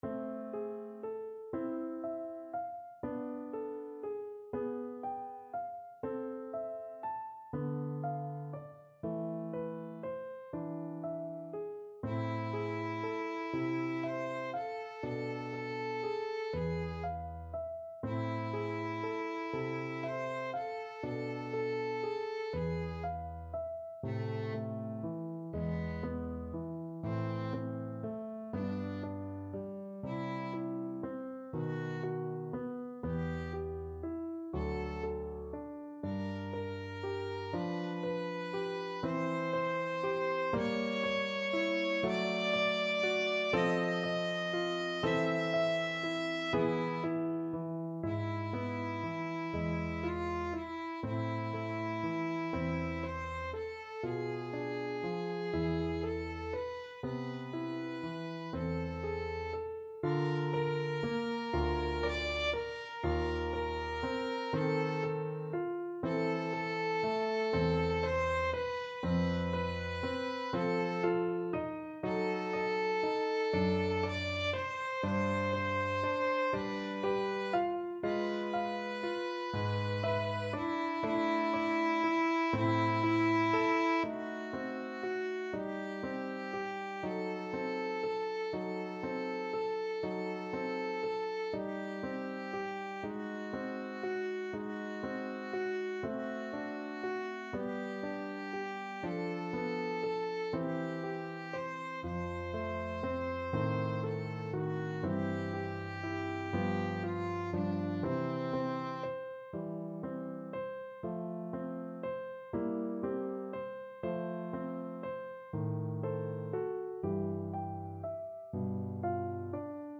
12/8 (View more 12/8 Music)
= 120 Larghetto